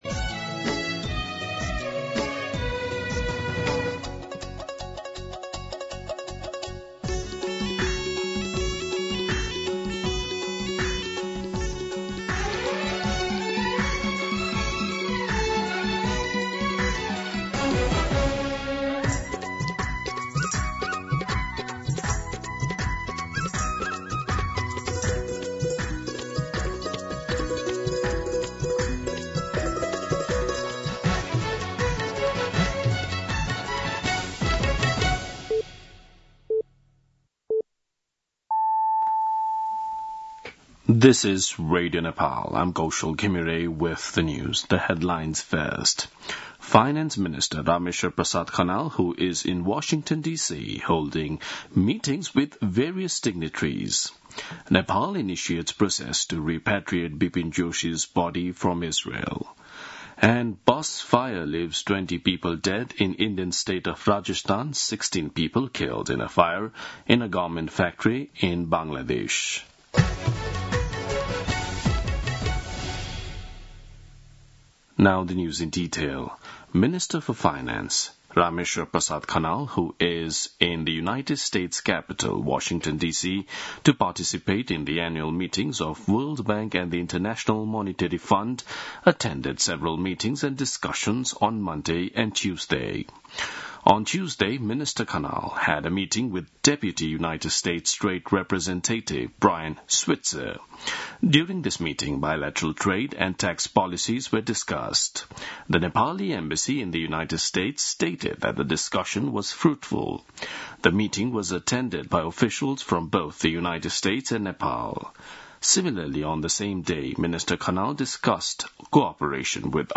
दिउँसो २ बजेको अङ्ग्रेजी समाचार : २९ असोज , २०८२